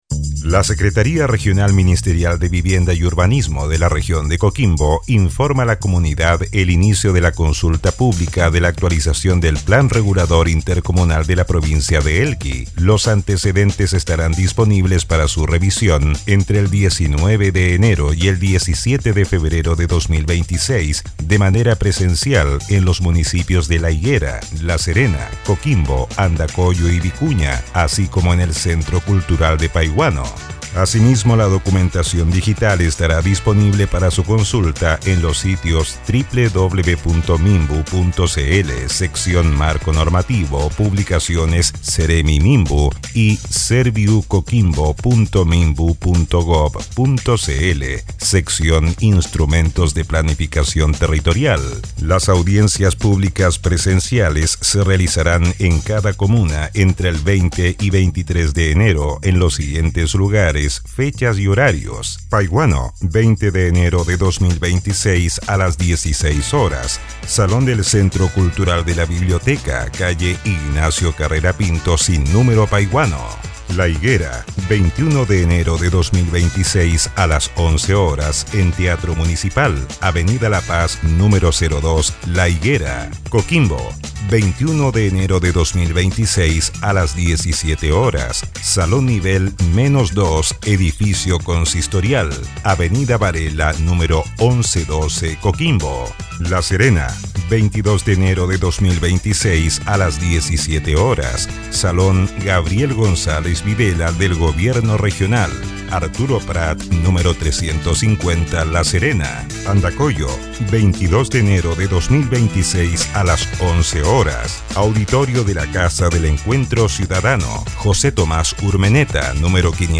Audio: Difusión Radial
6.-Aviso-Difusion-radial-PRI-Elqui.mp3